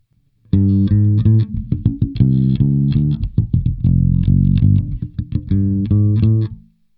Nahrál jsem jen "čistý" zvuk, rozdíly se zkreslením jsou ještě exponenciálně větší. U všech nahrávek jsem měl nastaven ekvalizér na AOU stejně, jak je vidět na fotkách v recenzi, na base jsem měl jen lehce přidané výšky, stejně jak jsem dělal nahrávky pro hlavní oddíl recenze.
Forrester's Clear - opět klasický box 8x10" bez bližších specifikací.